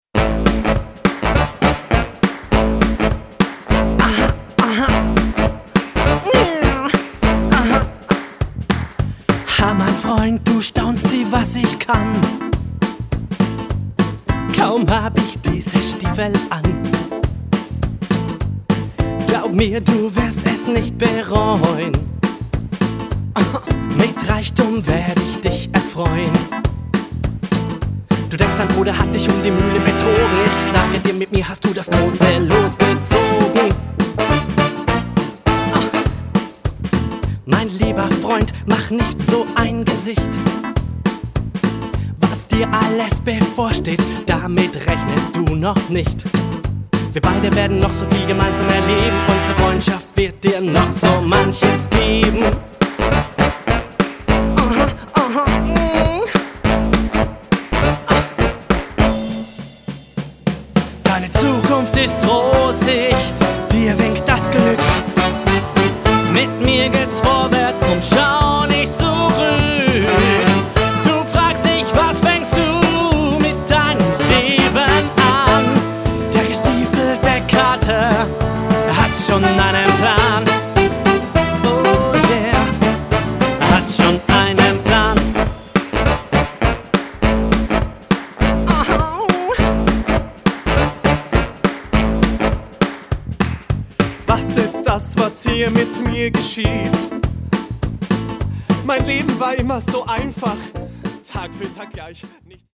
man erlebte ein Kindermusical das einmal mehr bewies